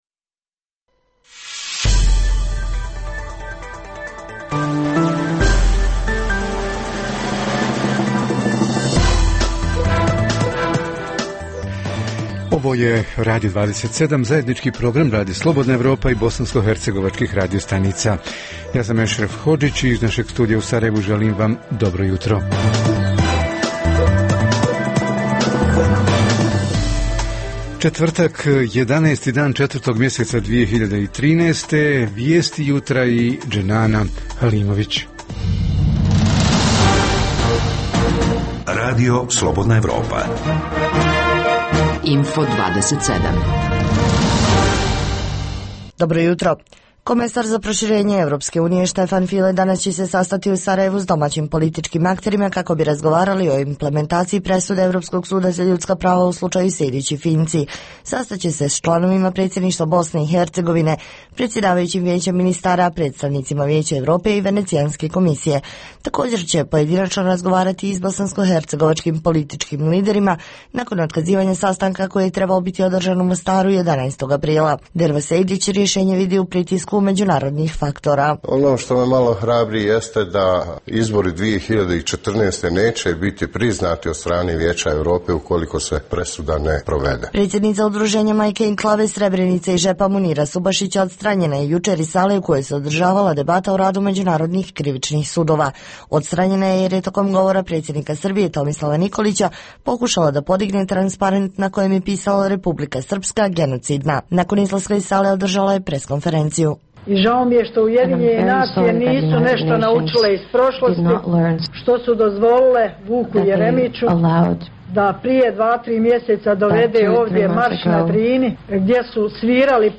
O tome će s više detalja naši reporteri iz Banje Luke, Travnika, Mostara i Doboja.